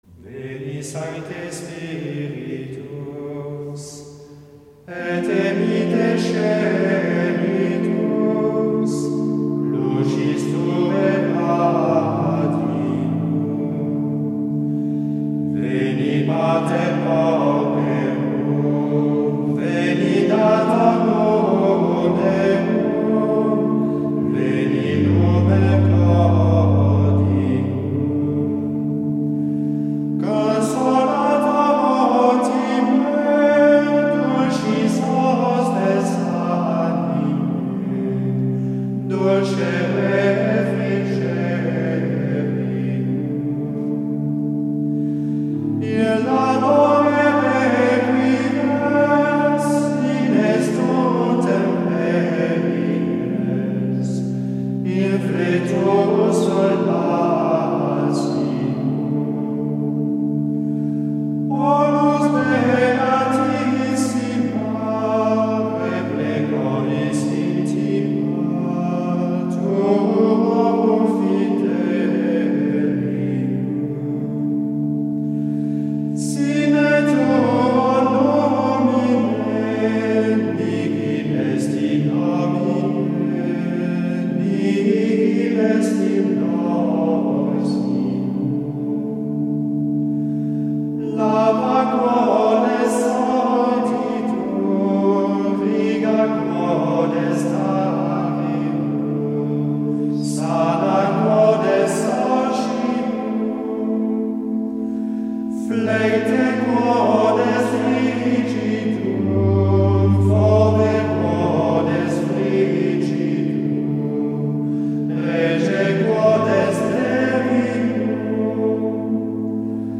Se entiende comúnmente por canto gregoriano un género de música a una sola voz, de tonalidad diatónica en general y ritmo libre.
214-veni-sancte-secuencia.mp3